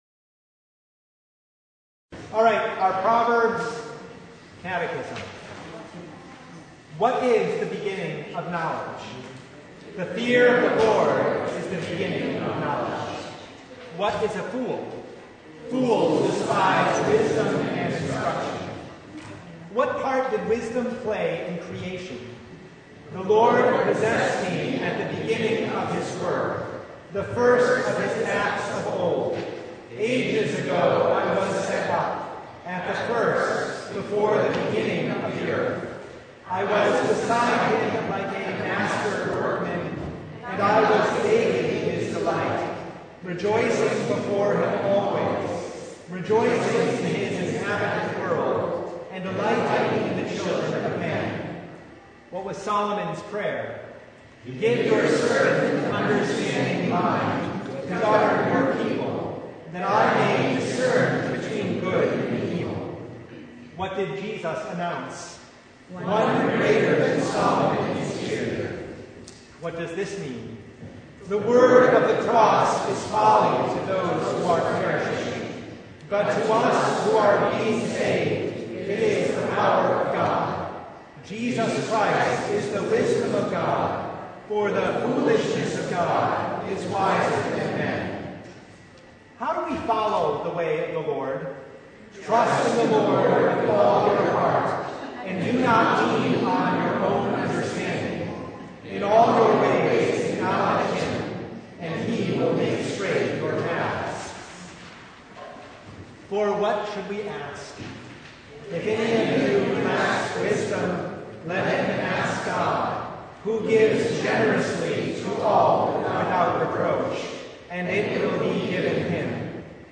Proverbs 1 Service Type: Bible Hour Key passages from Proverbs and other places in Holy Scripture regarding wisdom.